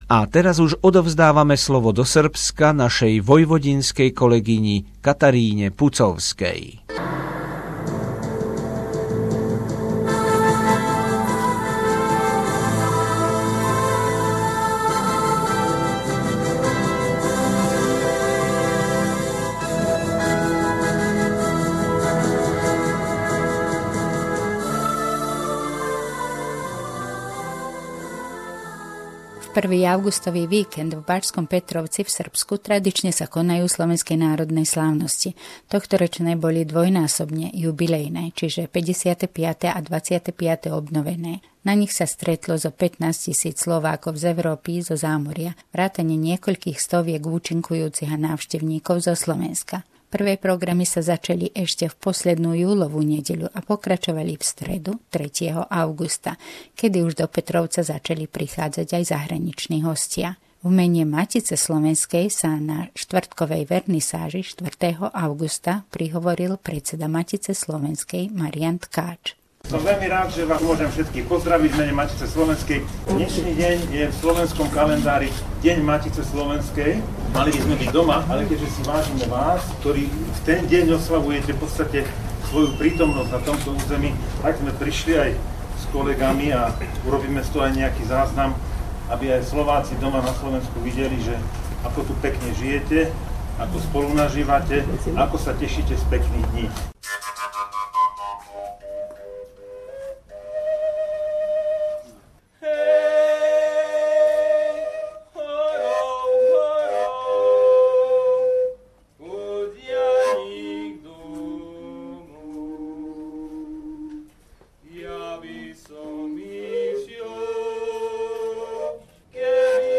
Slovenské národné slávnosti 2016 vo Vojvodine